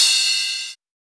Dilla Open Hat 08.wav